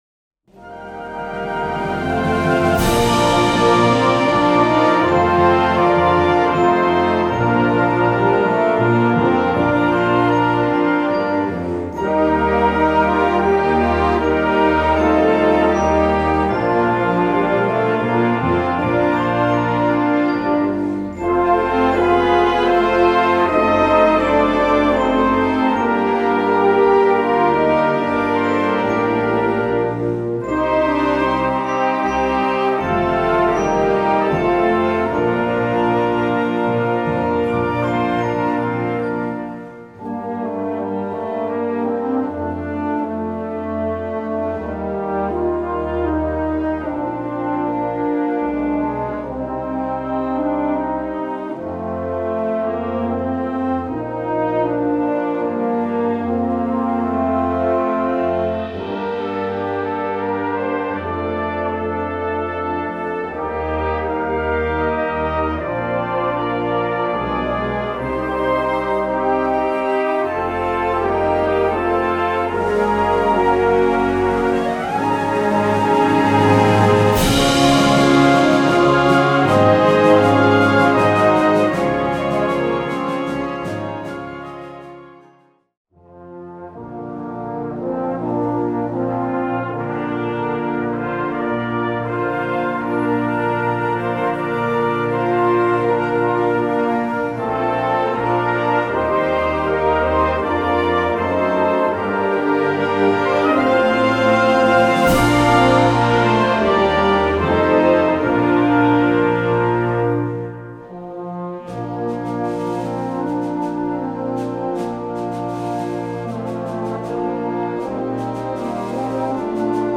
Gattung: Weihnachtslied
Besetzung: Blasorchester